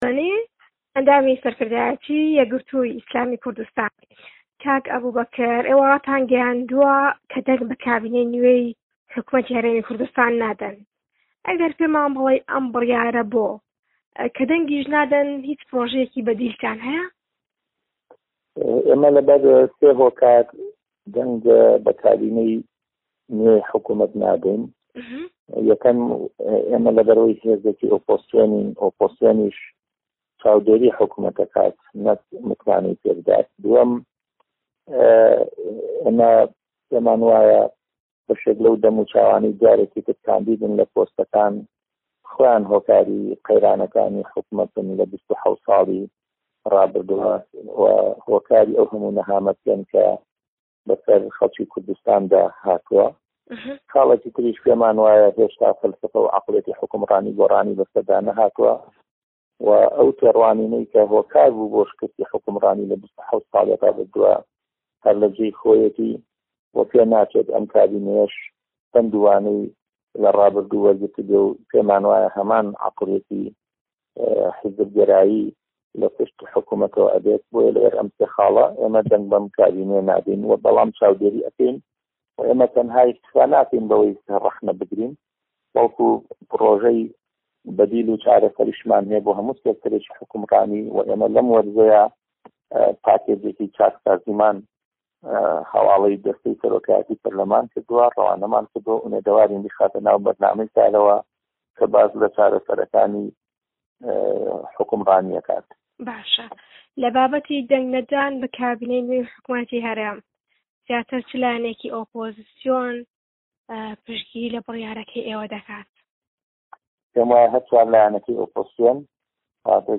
هه‌رێمه‌ کوردیـیه‌کان - گفتوگۆکان
وتووێژ لەگەڵ ئەبوبەکر ھەڵەدنی